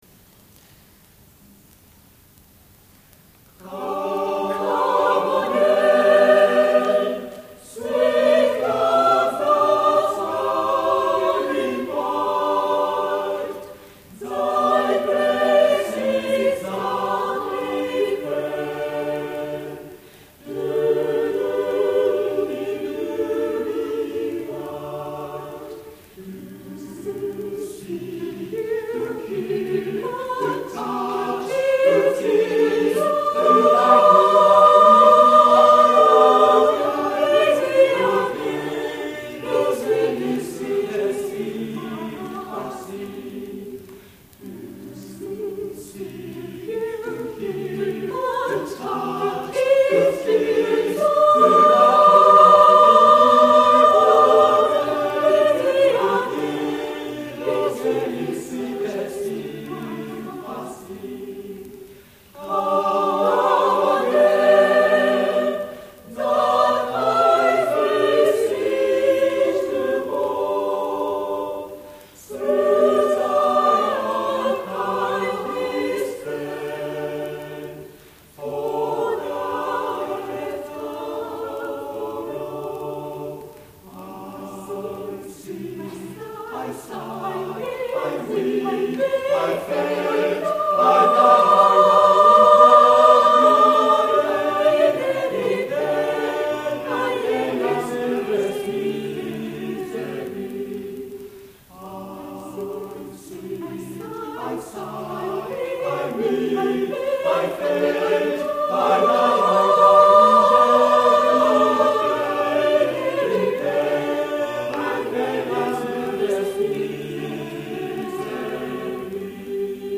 第33回野田市合唱祭
野田市文化会館